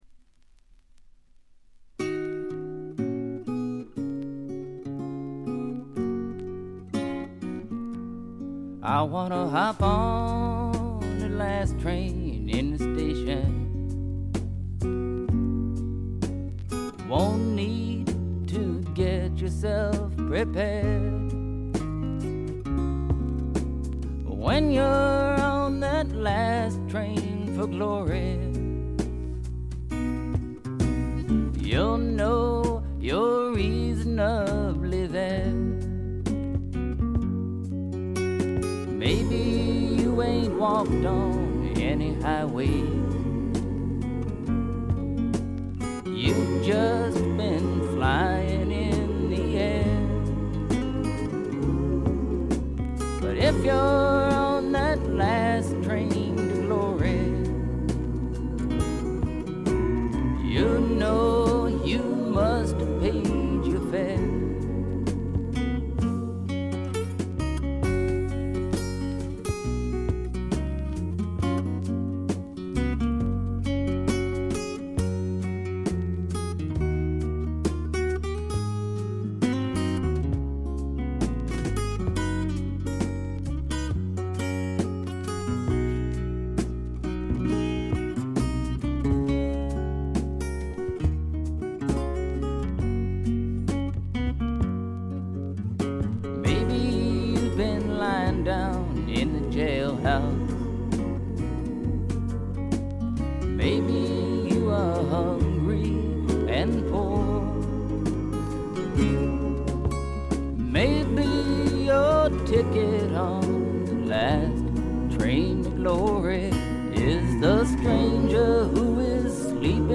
これ以外はほとんどノイズ感無し。
試聴曲は現品からの取り込み音源です。